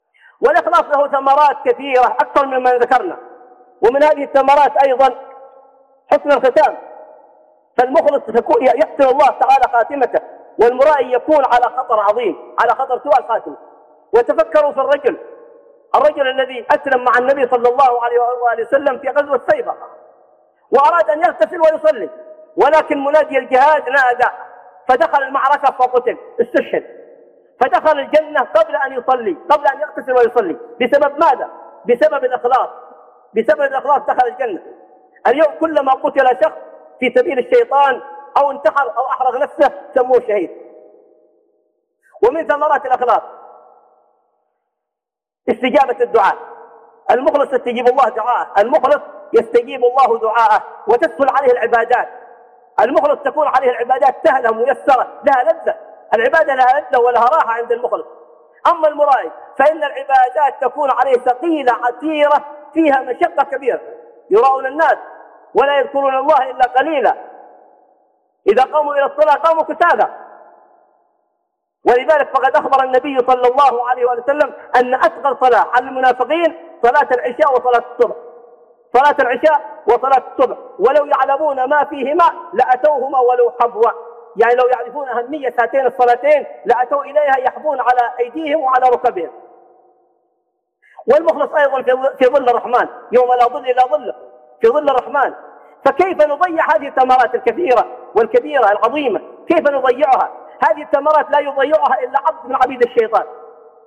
من ثمرات الإخلاص 2 - خطب